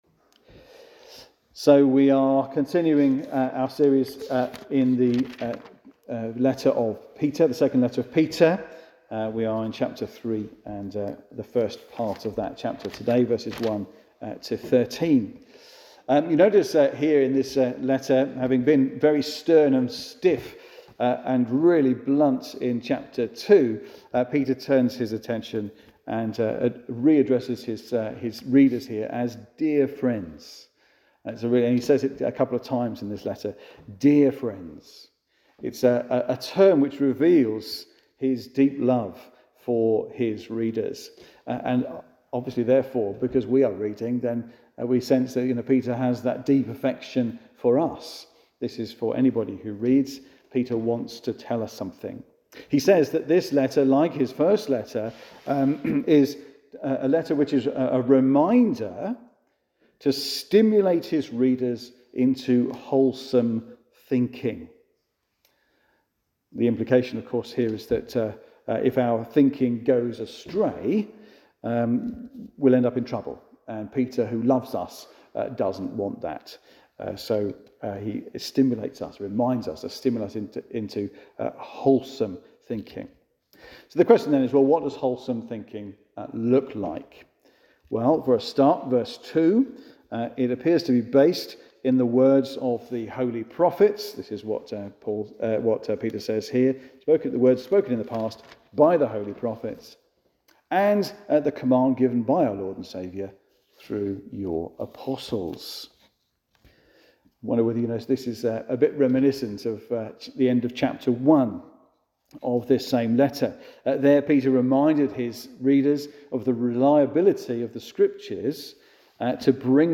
Sermons - Hunsdon Church